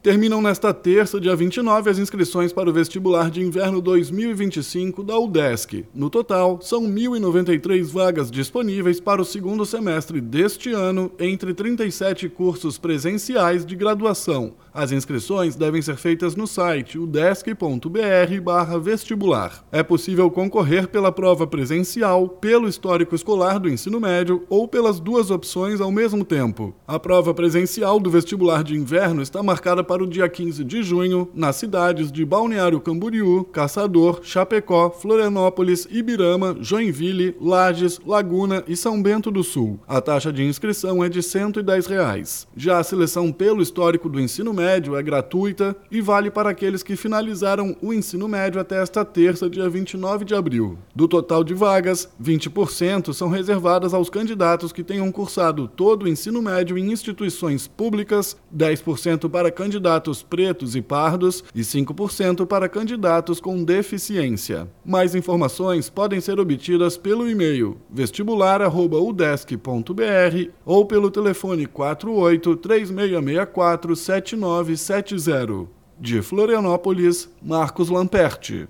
BOLETIM – Inscrições do Vestibular de Inverno 2025 da Udesc vão até esta terça-feira